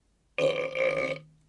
打嗝 1
描述：同一个男人另一个打嗝